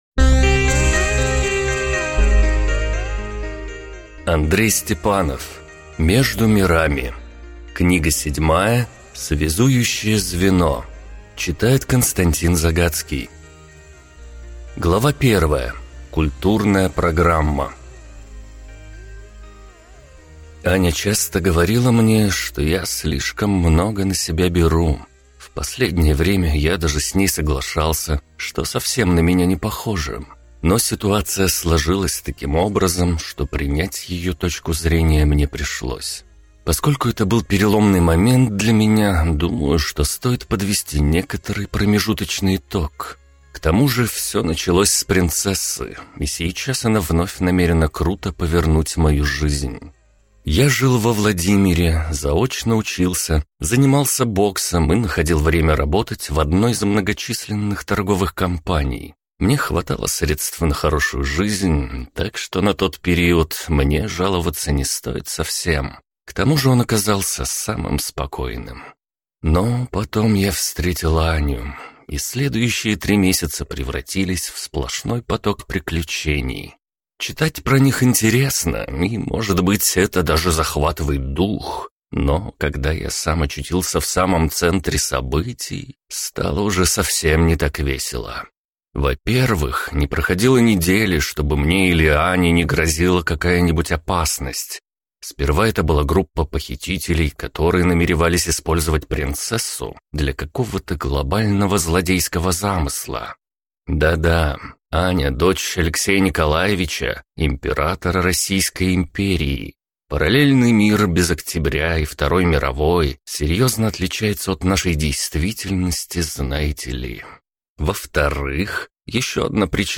Аудиокнига Между мирами: Связующее звено | Библиотека аудиокниг
Прослушать и бесплатно скачать фрагмент аудиокниги